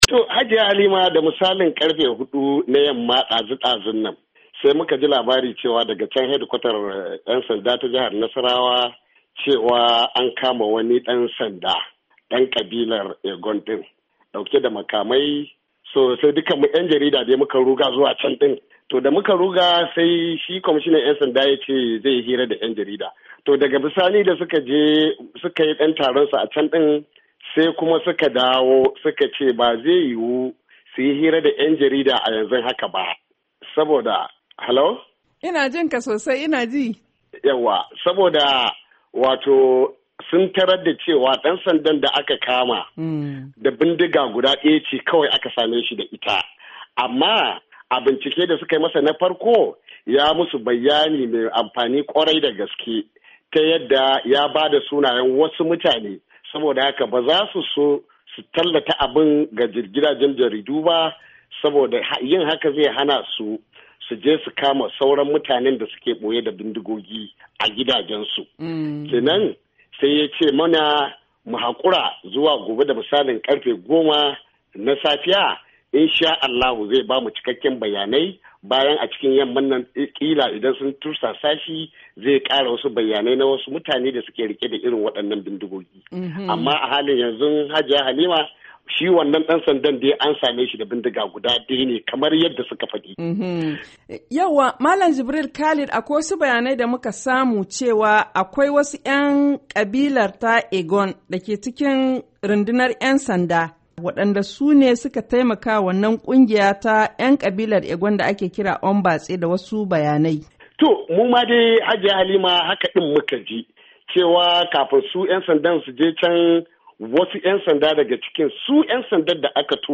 Tattaunawa